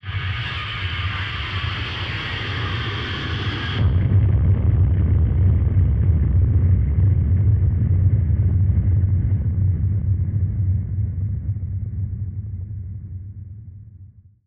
BF_DrumBombDrop-06.wav